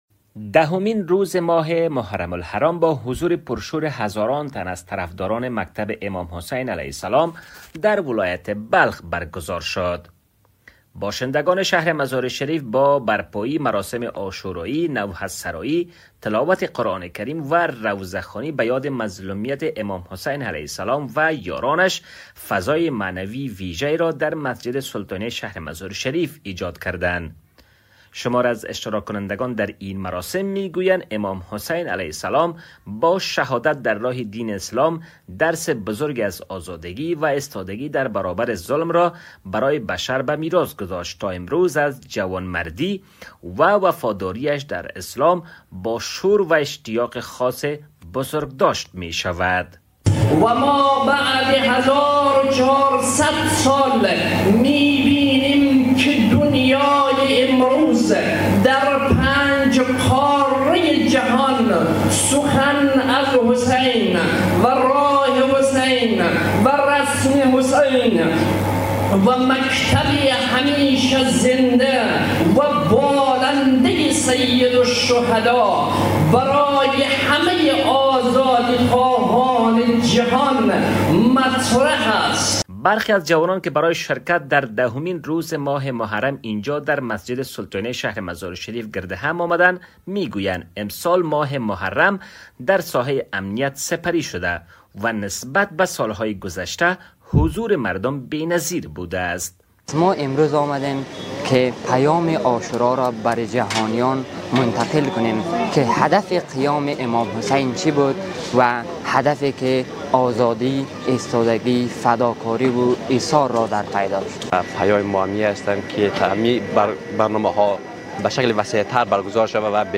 همزمان با دهمین روز از ماه محرم، هزاران تن از طرفداران مکتب امام حسین (ع) مراسم پرشوری در ولایت بلخ برگزار کردند.
گزارش